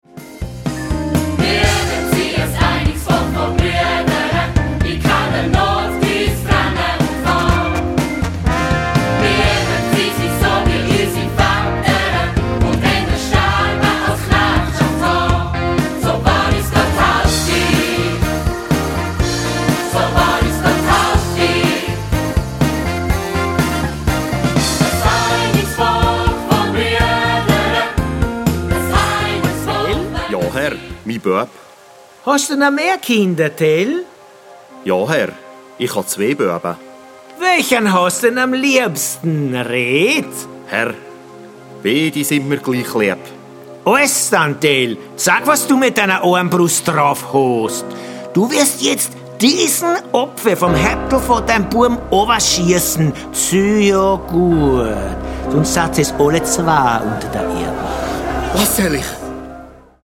Popmusical